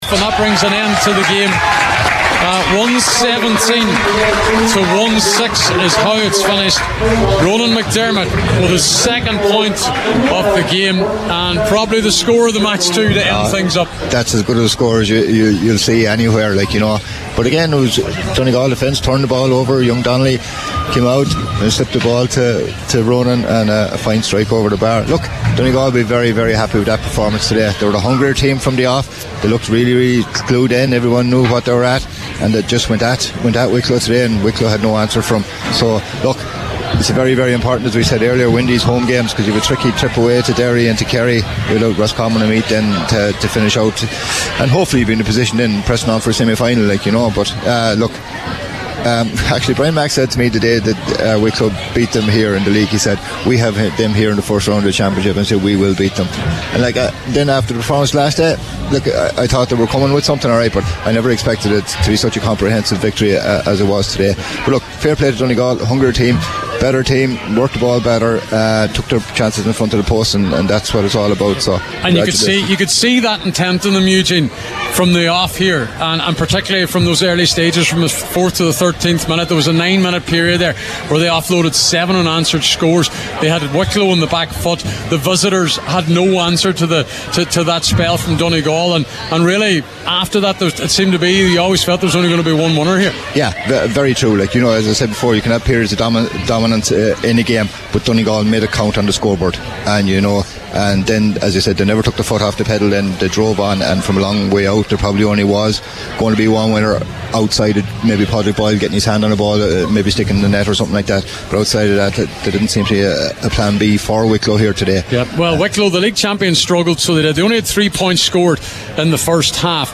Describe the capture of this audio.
were live at full time in Letterkenny…